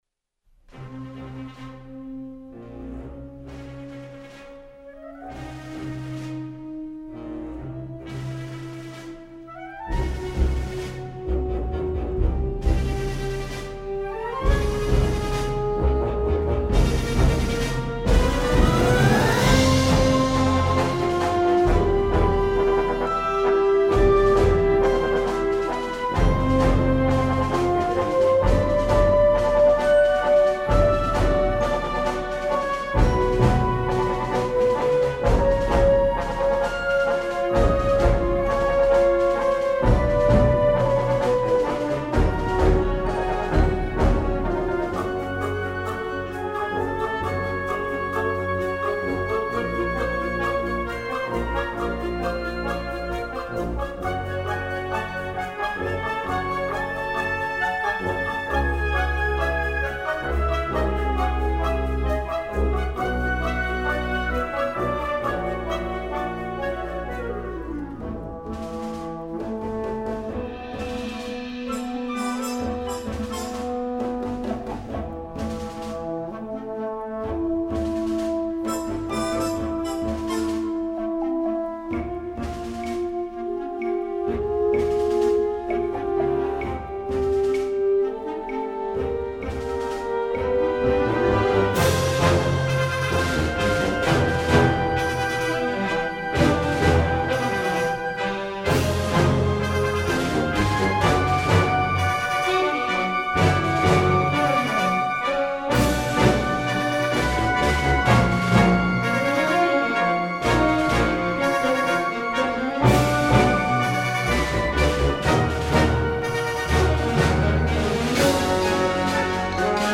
Instrumentation: concert band
pop, instructional